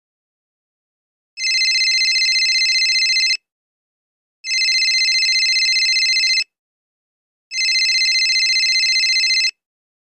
Cell Phone Ringing Sound Effects